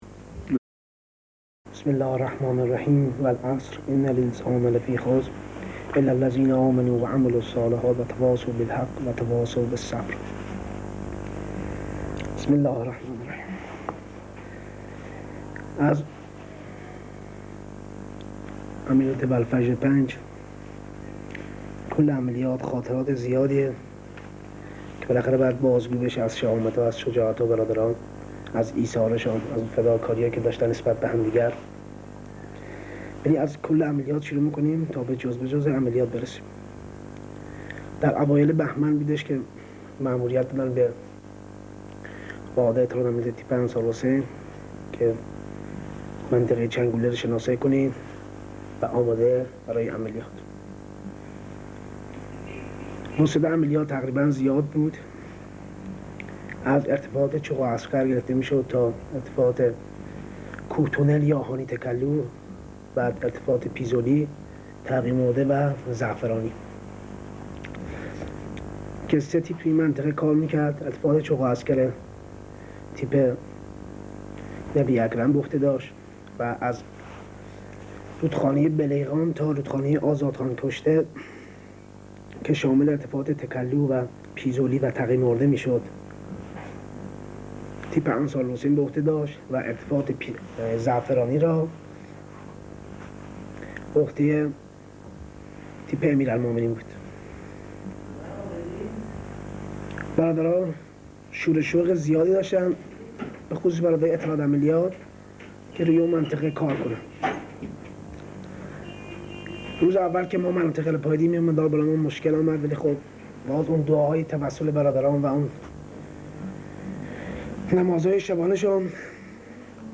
صوت/ شرح عملیات والفجر 5 با بیان خاطره انگیز سردار شهید علی چیت سازیان
فایل تصویری این مصاحبه در آرشیو بنیاد شهید استان همدان موجود است